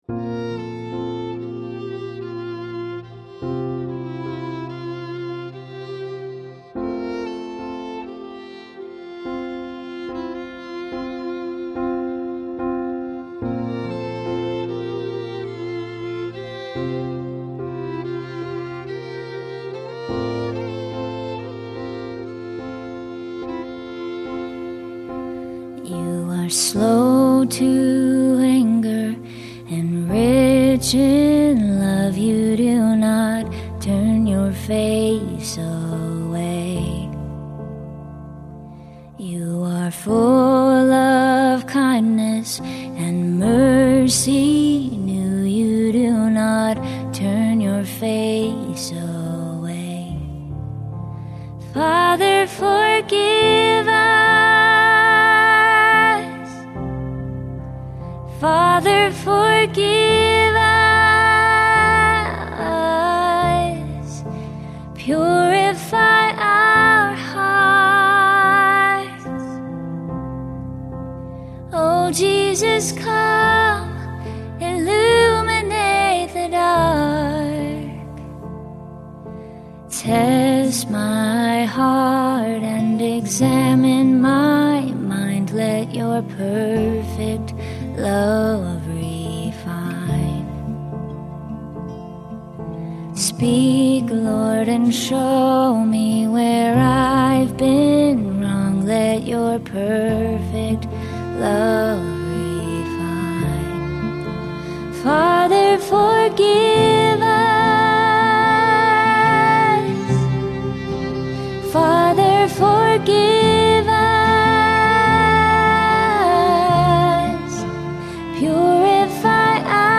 Lent: Meditation and Worship